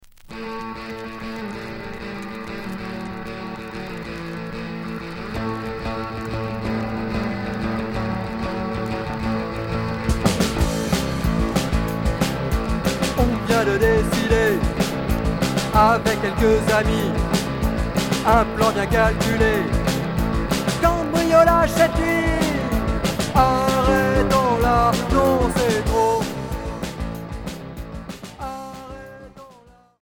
Rock punk